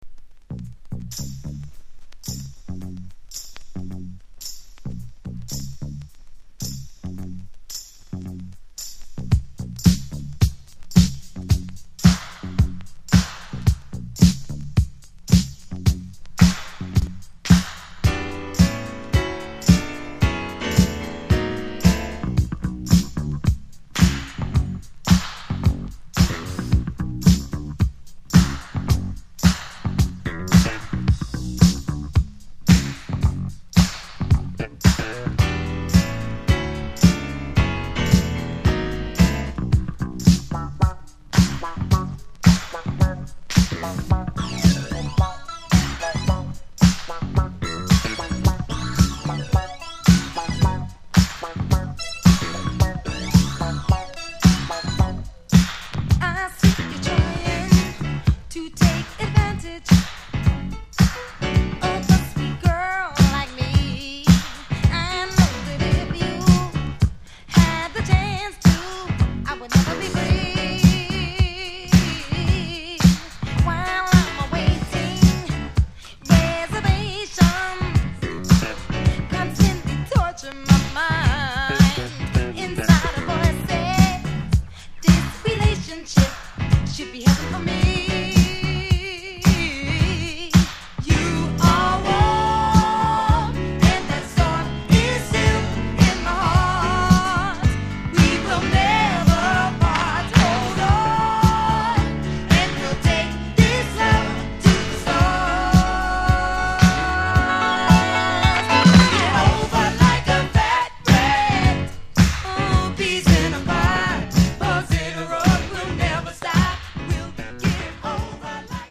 NY MODERN DISCO CLASSIC！
NY出身の女性シンガー